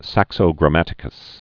(săksō grə-mătĭ-kəs) 1150?-1220?